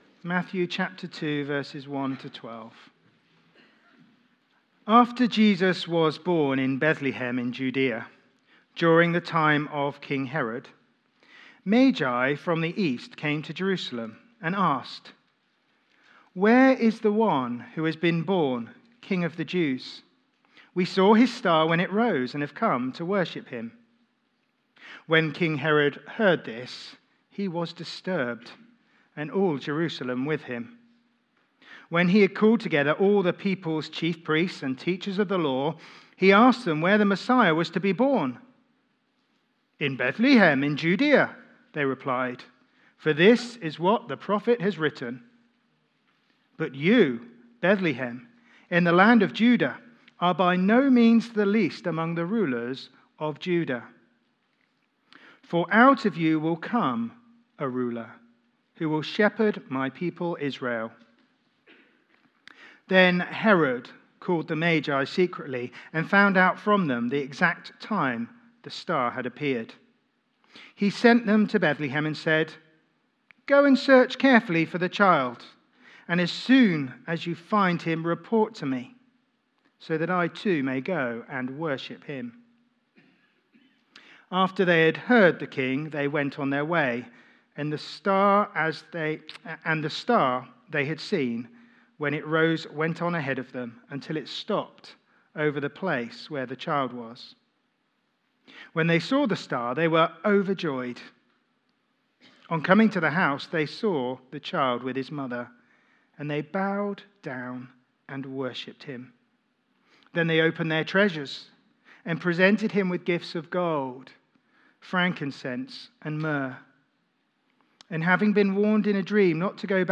Media for Sunday Service
Theme: The Gift of Love Sermon